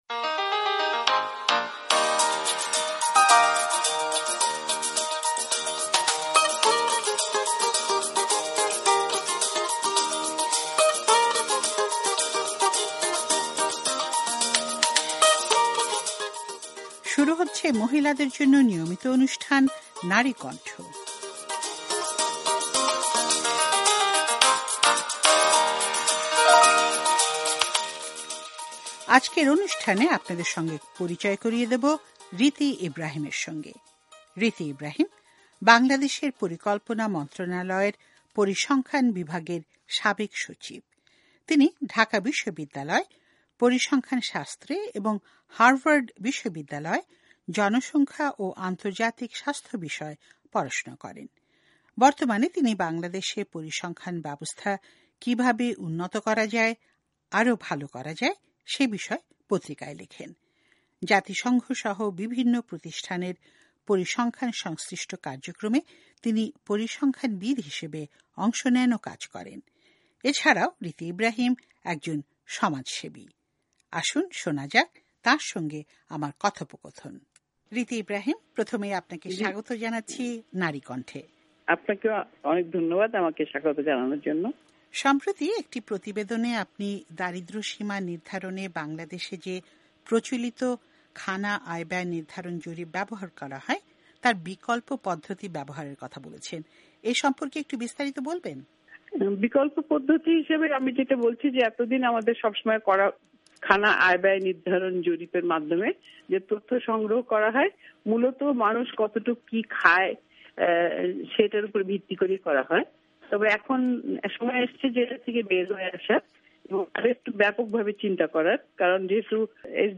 নারী কন্ঠ : পরিসংখ্যানবিদ রীতি ইব্রাহিমের সঙ্গে কথোপকথন